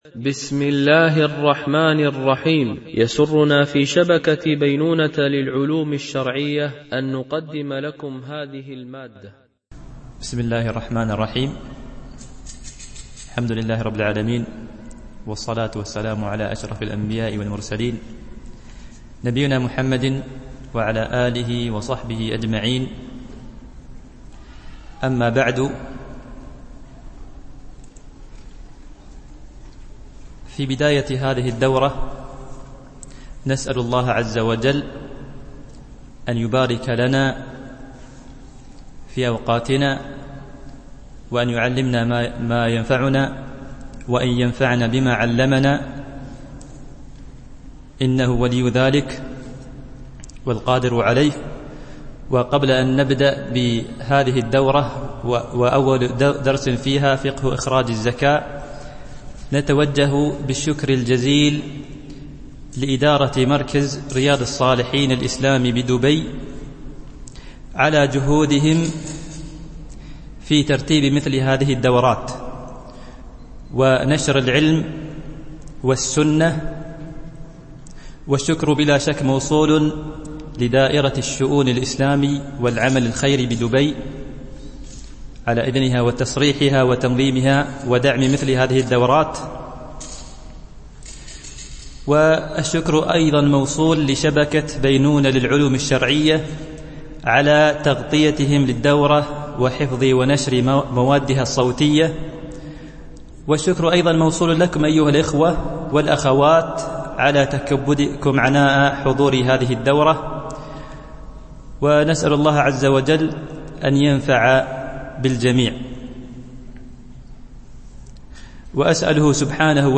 دورة علمية شرعية، بمسجد أم المؤمنين عائشة - دبي